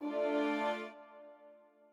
strings2_16.ogg